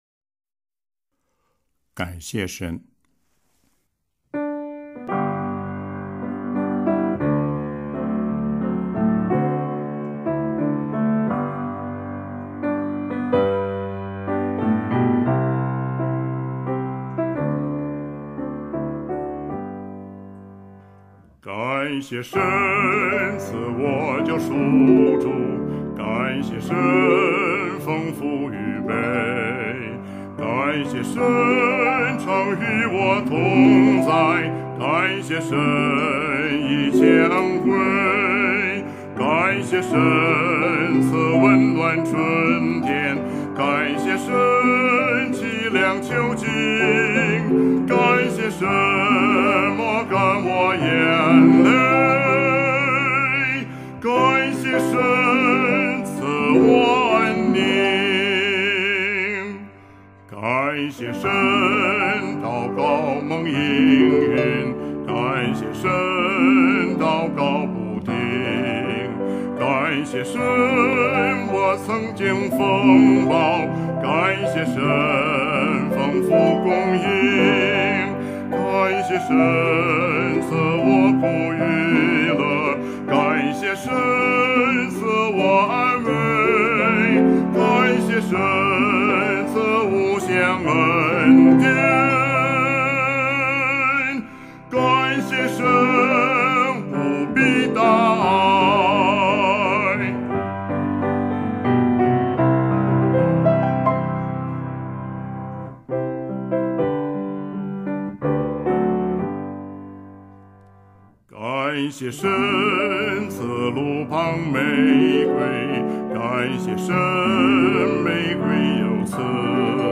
赞美诗《感谢神》